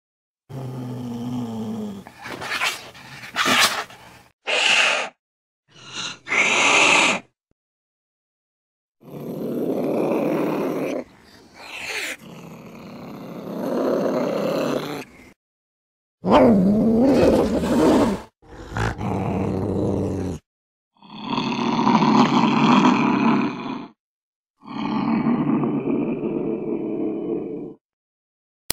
دانلود آهنگ گربه وحشتناک و ترسناک از افکت صوتی انسان و موجودات زنده
دانلود صدای گربه وحشتناک و ترسناک از ساعد نیوز با لینک مستقیم و کیفیت بالا
جلوه های صوتی